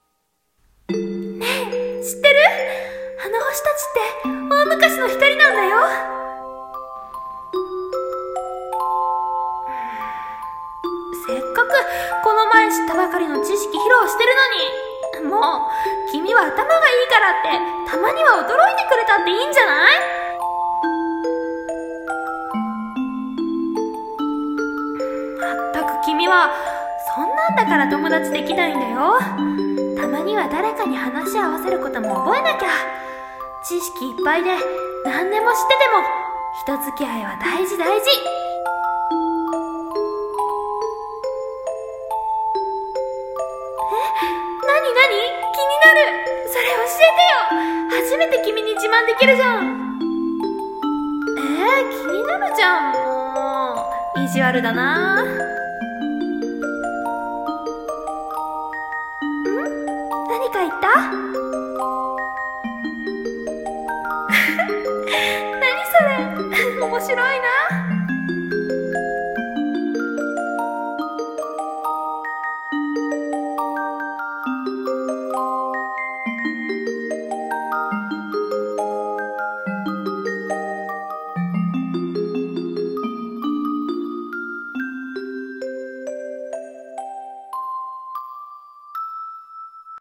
二人声劇